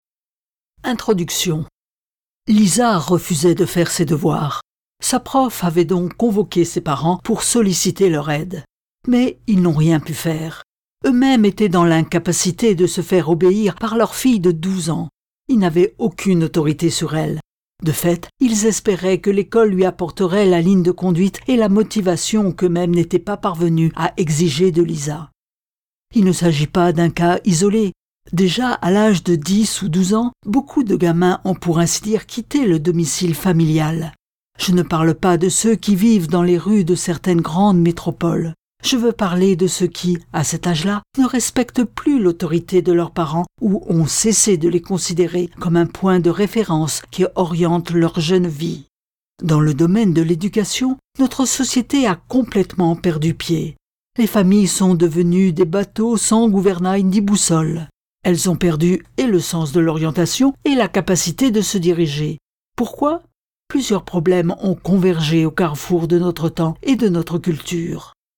Click for an excerpt - Fiers de toi fiston ! de Tedd Tripp